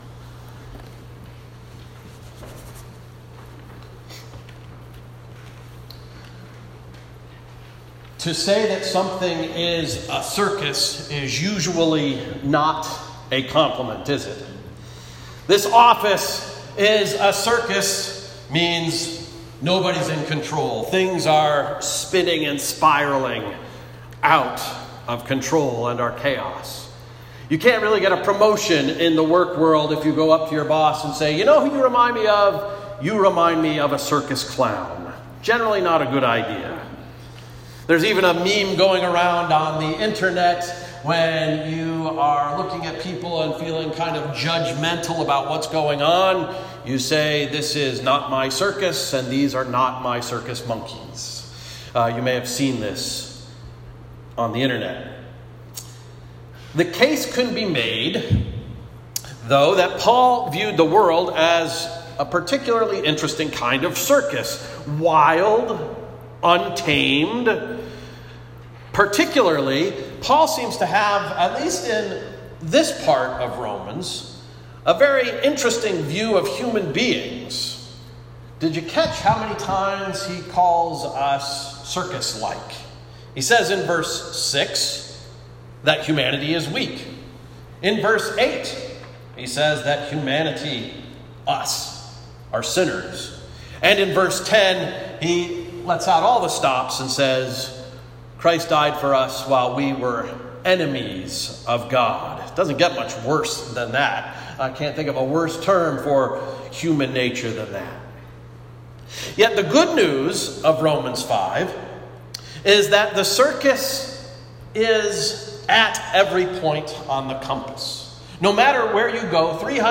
“The 360 Degree Circus” Sermon of May 26, 2019 – Emmanuel Reformed Church of the United Church of Christ